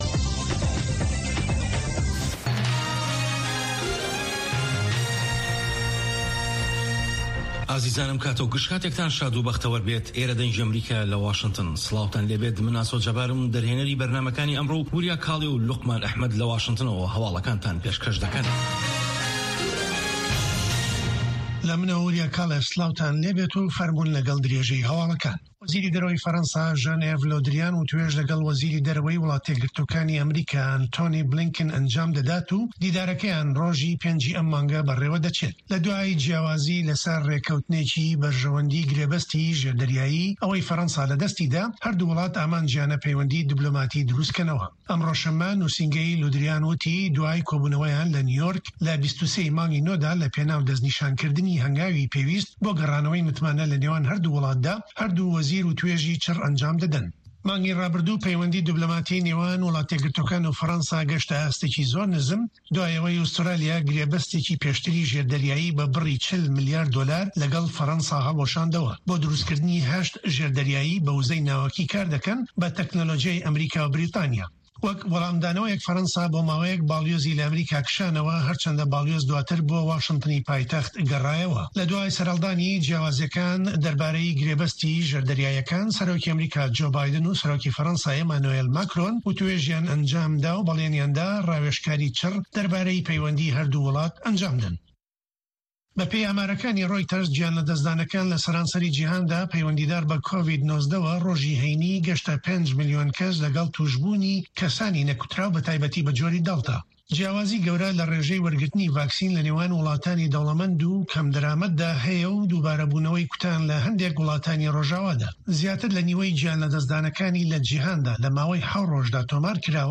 هه‌واڵه‌کان ، ڕاپـۆرت، وتووێژ.